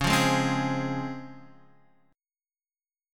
DbM7 chord